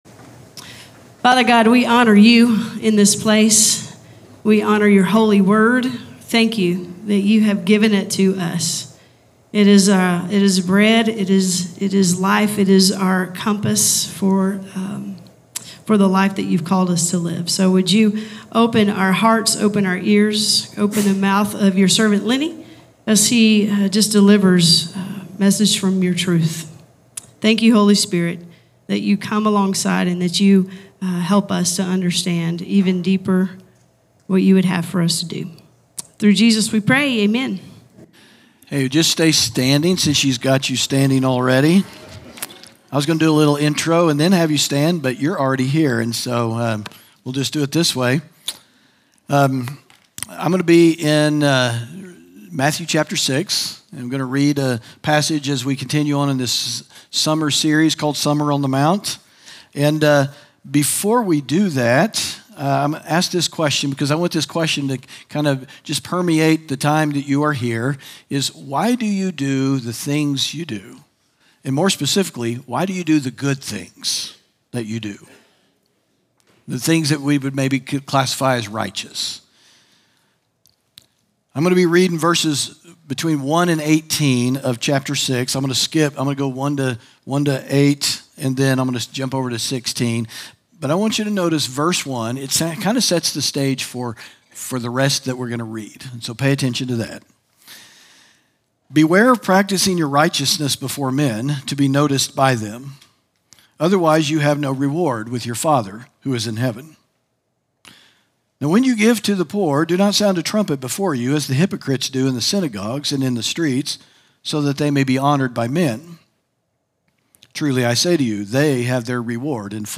sermon audio 0727.mp3